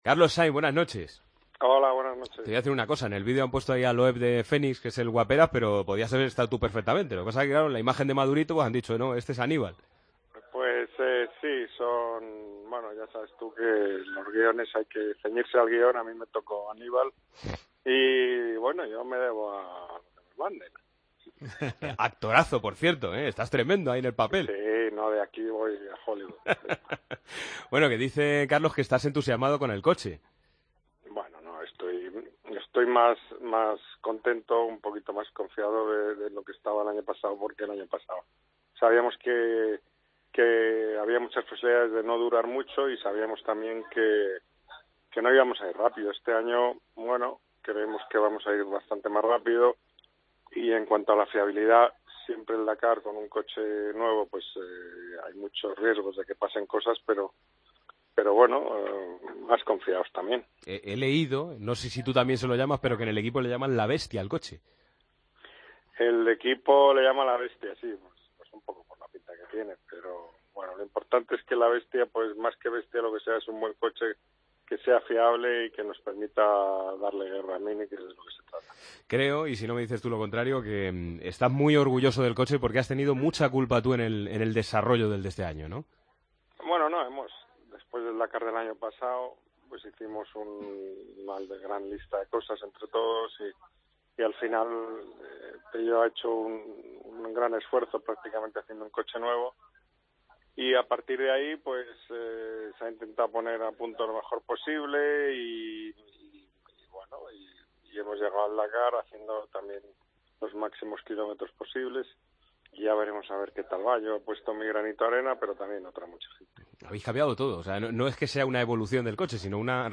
El piloto Carlos Sáinz analizó en El Partido de las 12 el Rally Dakar en el que competirá dentro de dos semanas: